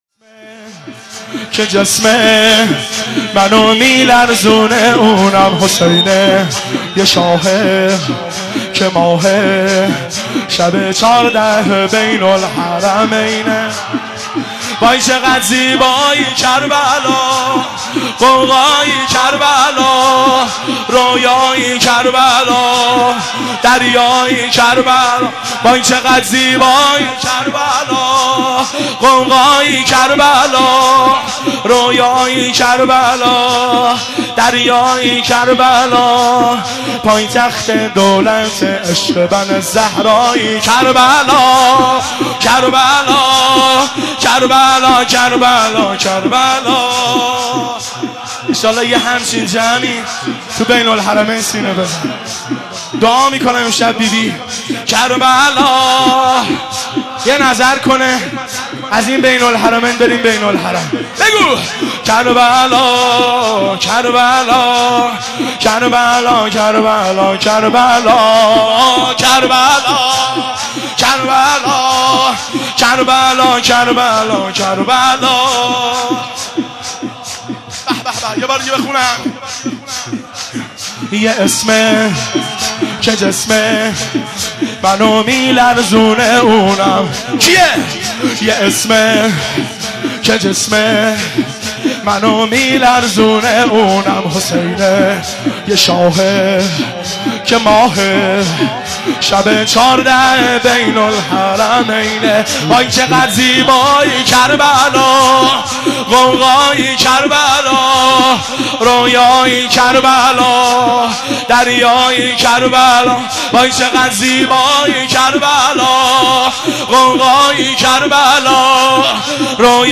مناسبت : ولادت حضرت فاطمه‌ زهرا سلام‌الله‌علیها
قالب : شور